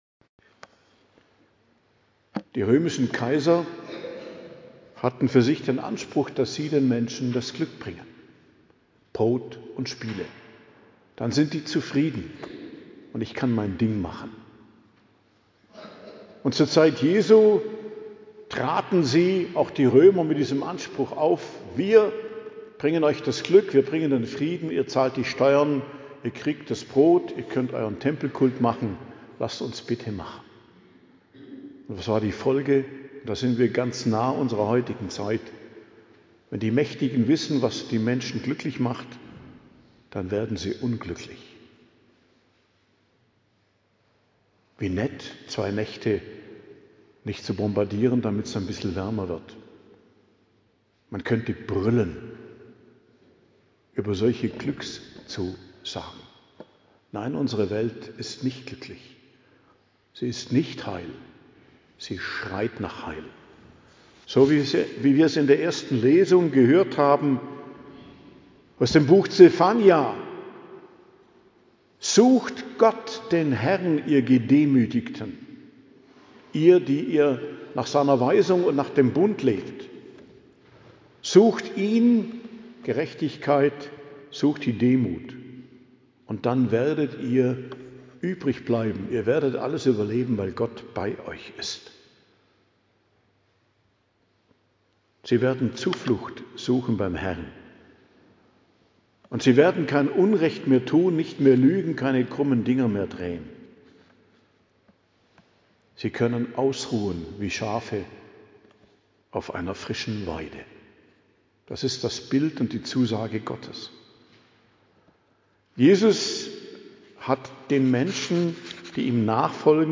Predigt zum 4. Sonntag i.J., 1.02.2026